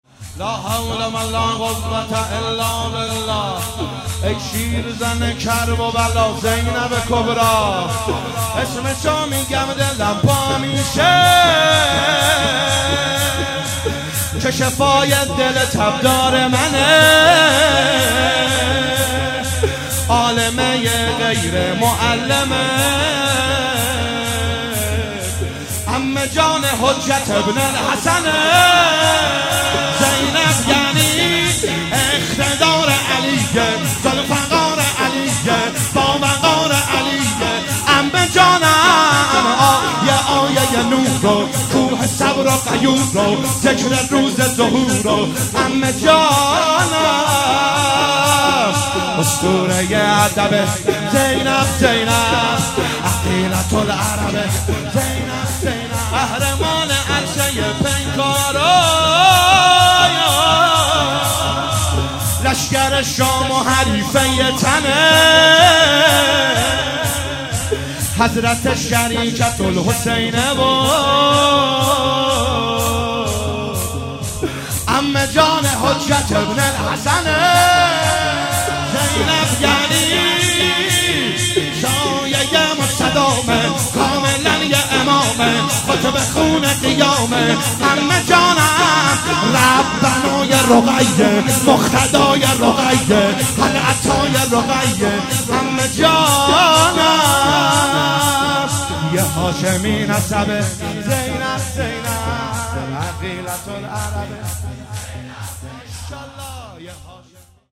ایام فاطمیه 1441 | محفل عزاداران حضرت زهرا (س) شاهرود